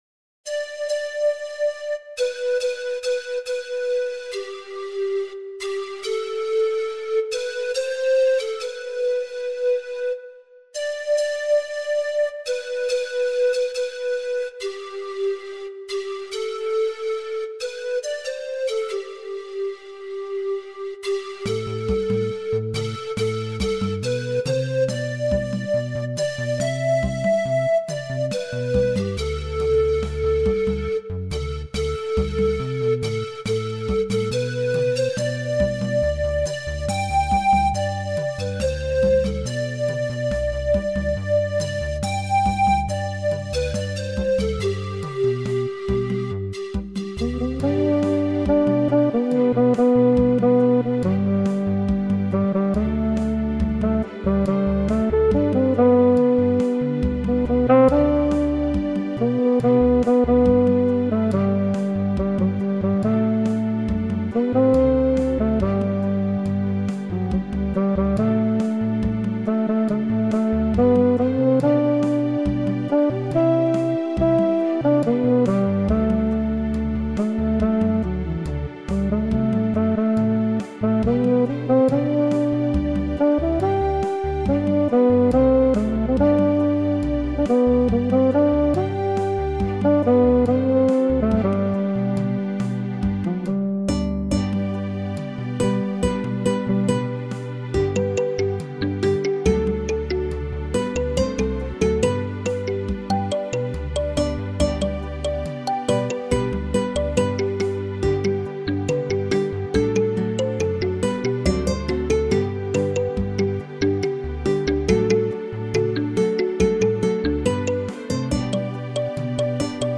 Romantic German "Volkslied"
Romantic folksong